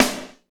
Index of /90_sSampleCDs/Northstar - Drumscapes Roland/DRM_R&B Groove/SNR_R&B Snares x